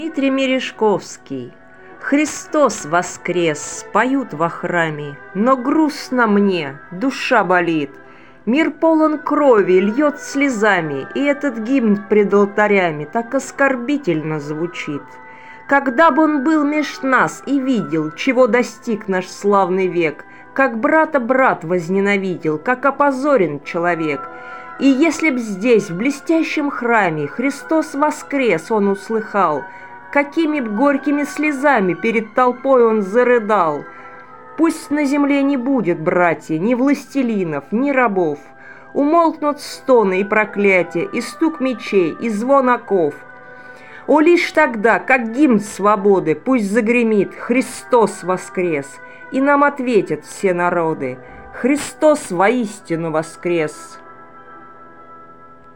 Музыка классики Озвучка